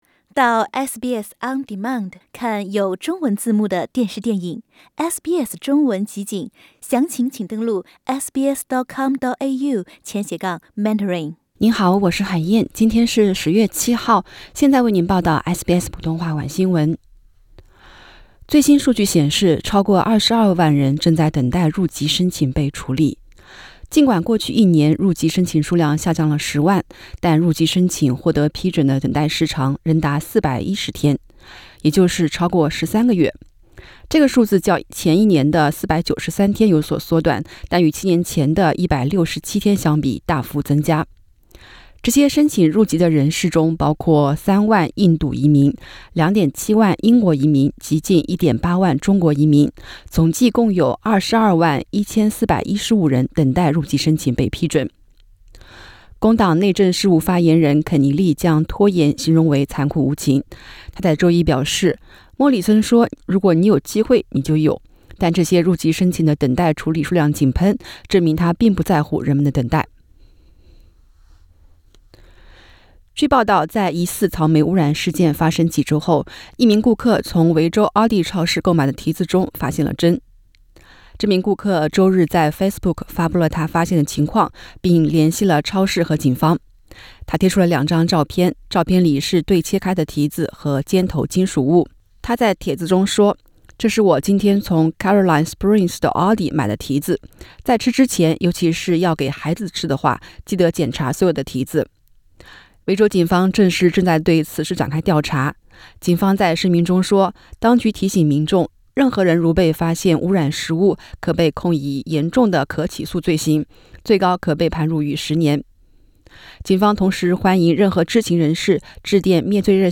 SBS晚新闻（10月7日）